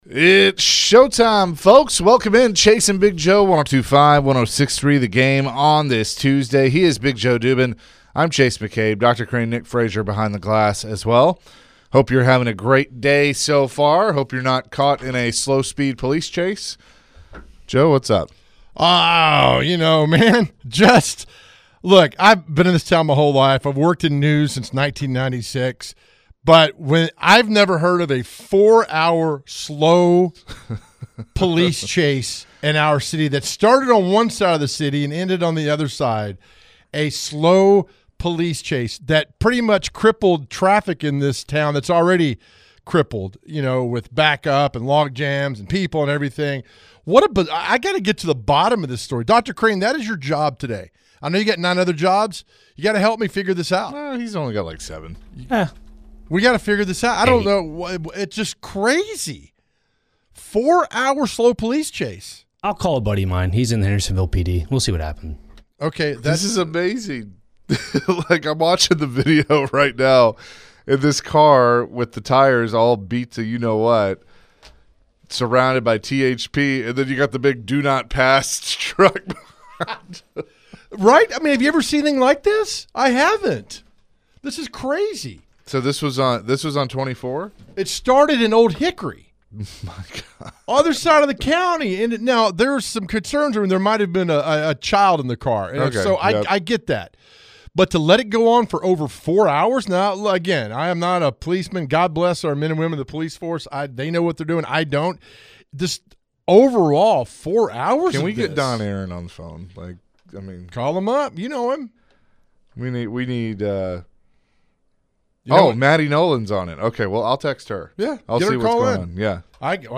Later in the hour, Professional Eater Miki Sudo joined the show. Miki shared how she prepares for the competitive eating competitions.